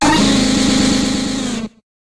Cri de Deusolourdo dans Pokémon HOME.